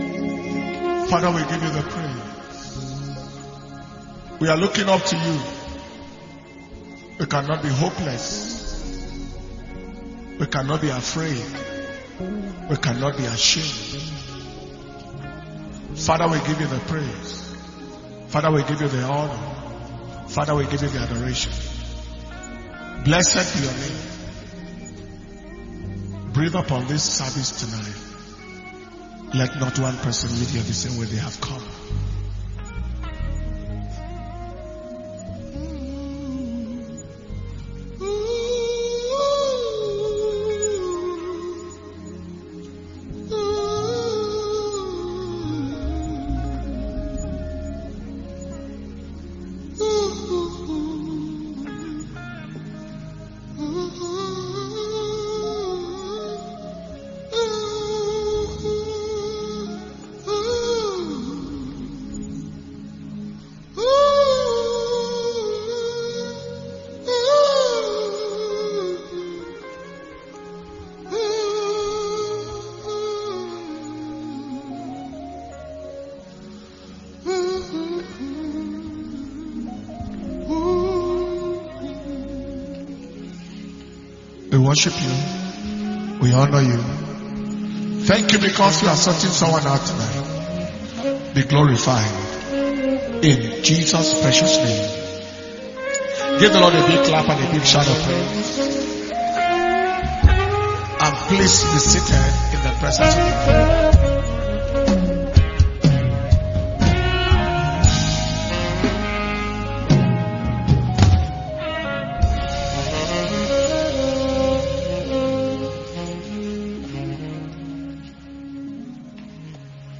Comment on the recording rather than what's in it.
Power Communion Service